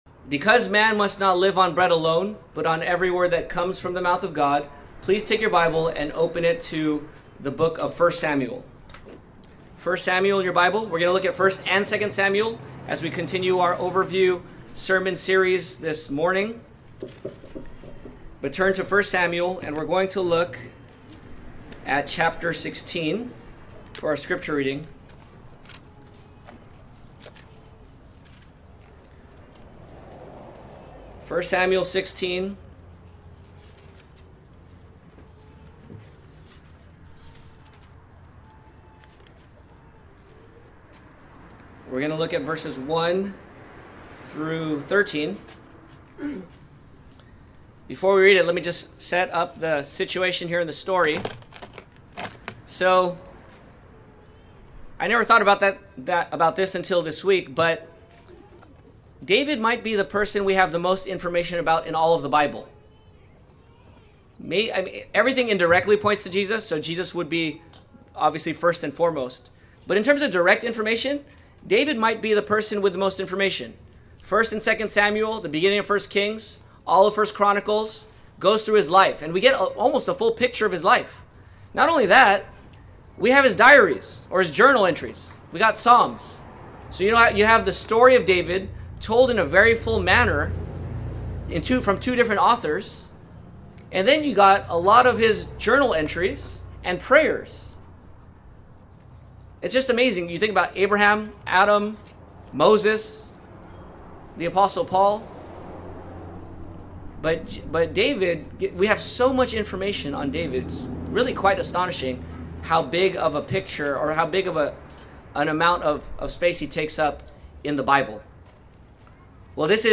OT Overview Sermons